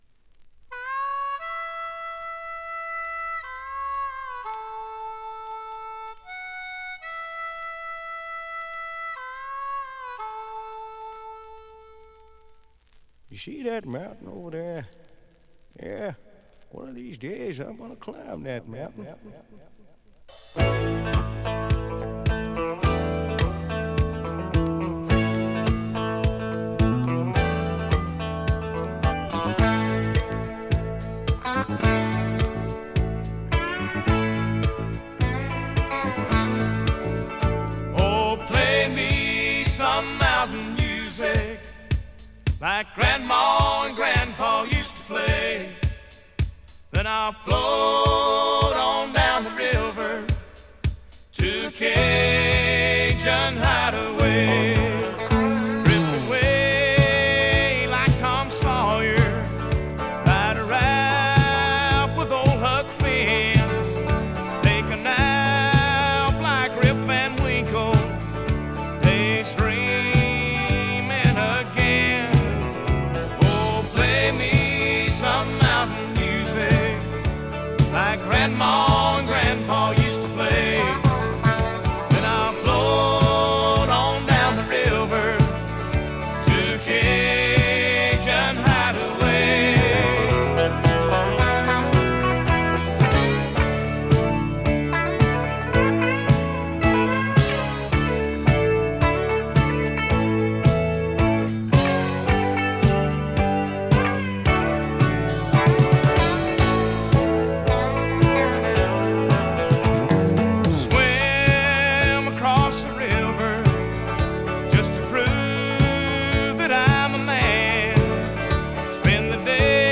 американская кантри-рок-группа.